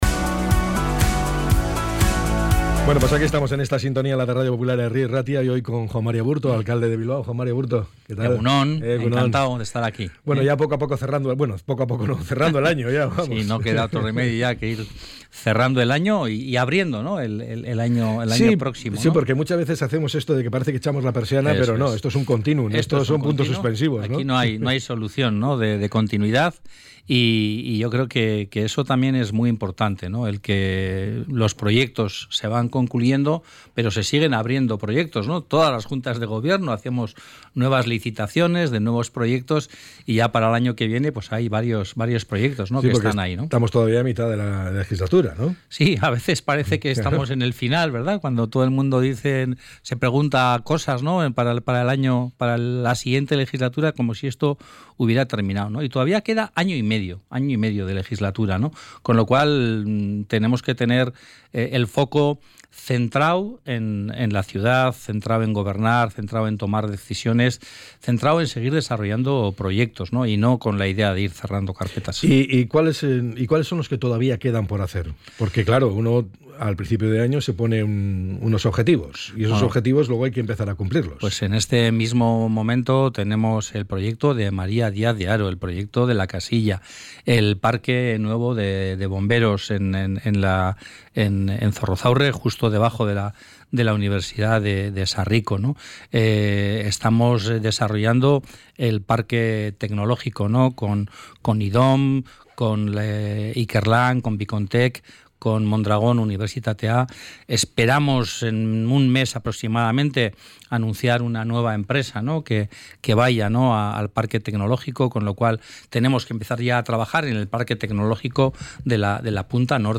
ENTREV.-ABURTO.mp3